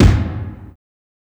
ILLMD005_KICK_MILLI_2.wav